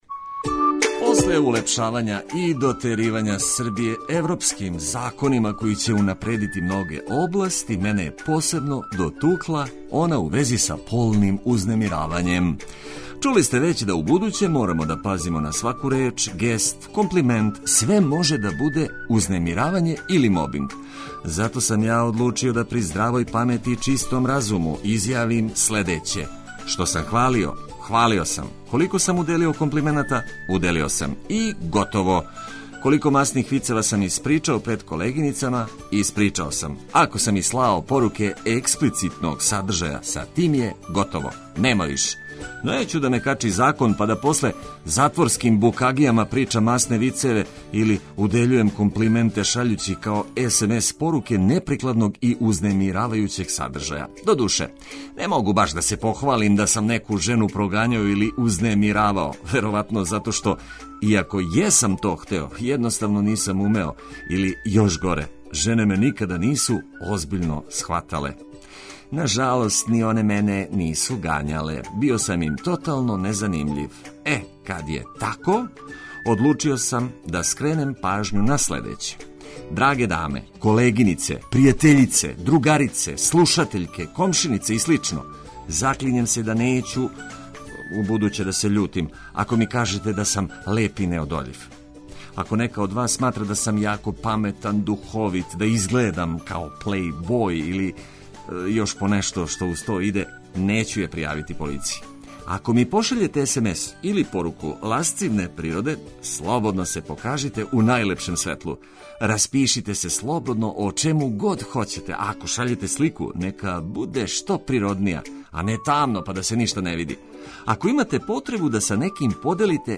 Чућемо и Љубивоја Ршумовића, једног од најбољих писаца, који ће нам дати неколико савета како да поступамо са децом, односно како да их правилно васпитавамо.